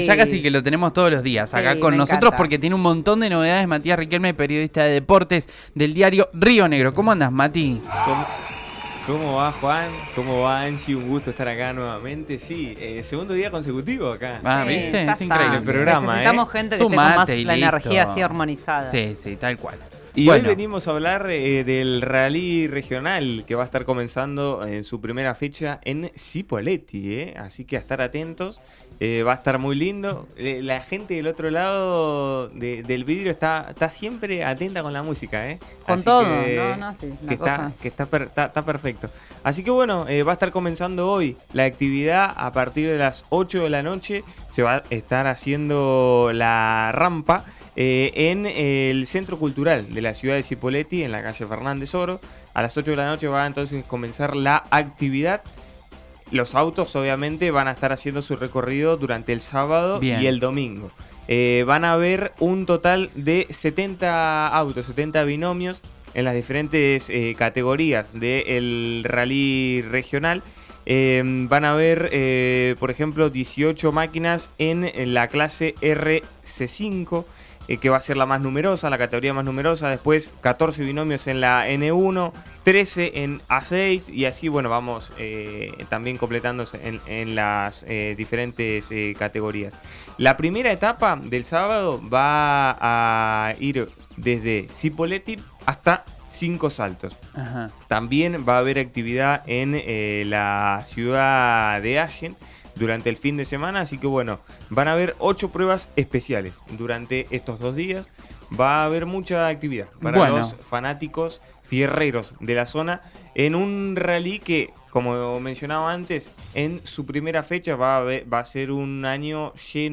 Escuchá la entrevista completa de RIO NEGRO RADIO